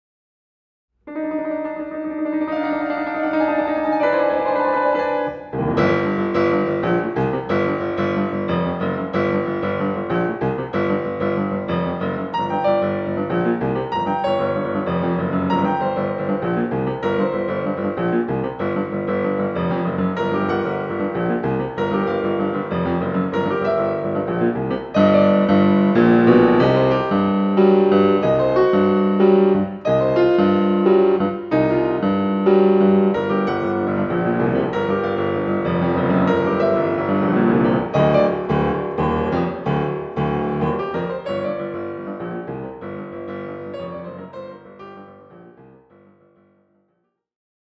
complete works for solo piano